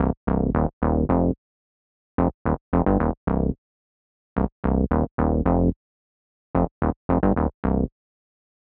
30 Bass PT4.wav